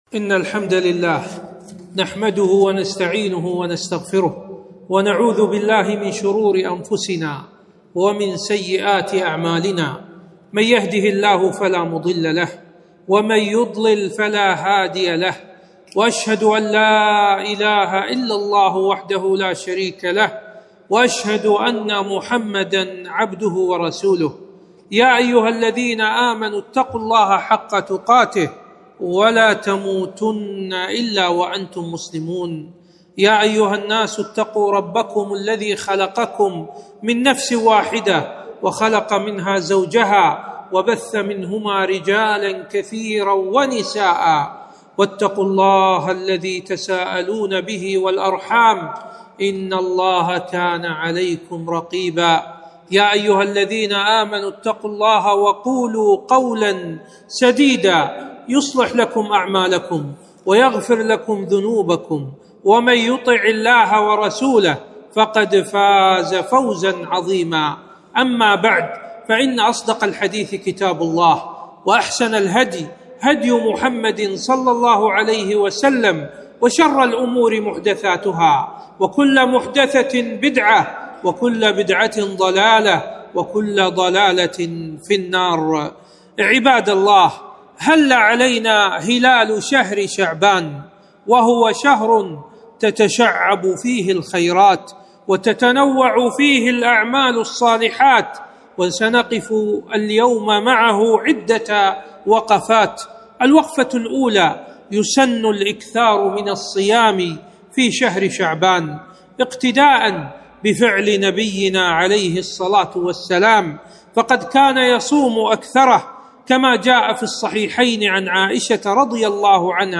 خطبة - وقفات مع شهر شعبان ١٤٤٧ هـ